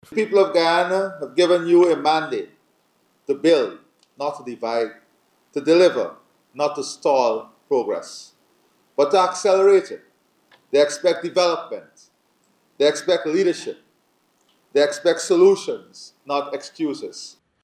He made these remarks during Thursday’s swearing in ceremony for the Regional Chairpersons and Vice Chairpersons at the Arthur Chung Conference Center.
In his address, President Ali expressed expectations that moving forward, Regional Democratic Councils will be more proactive and inspire confidence in the communities they serve.